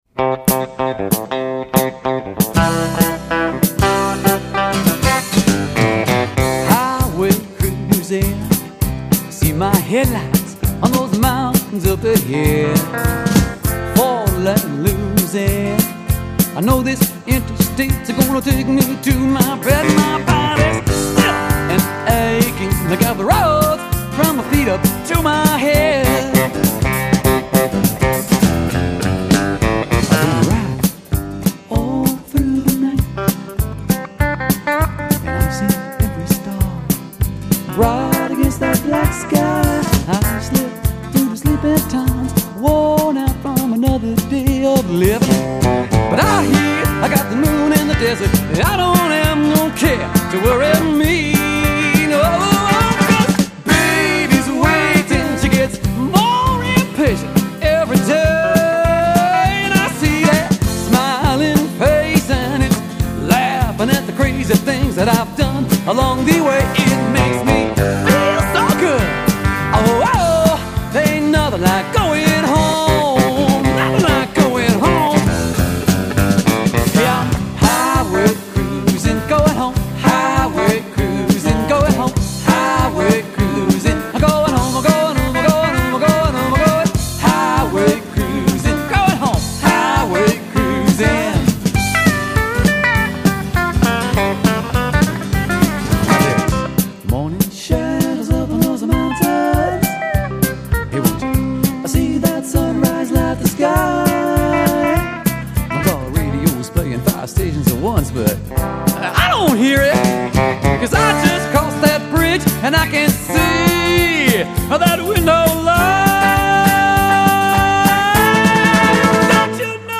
vocals, guitar
Bass
Drums
Telecaster